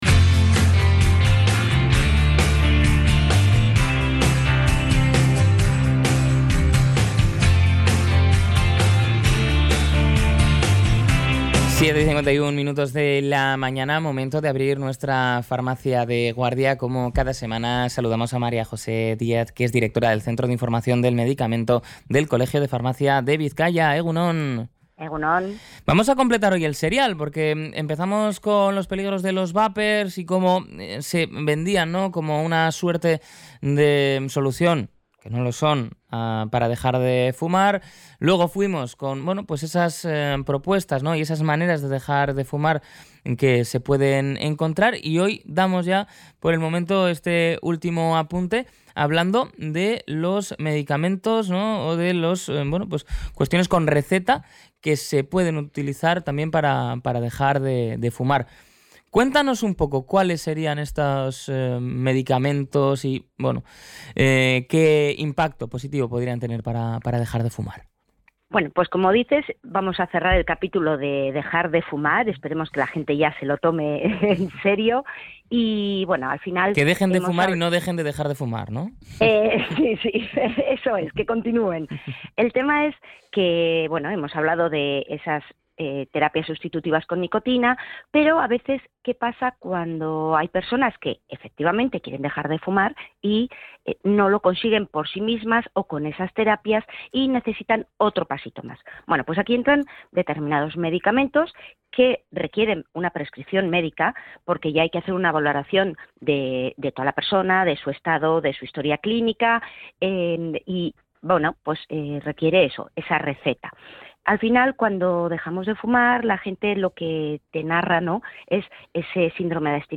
Durante la entrevista, se han detallado los tres principios activos principales que se utilizan actualmente bajo estricto control médico: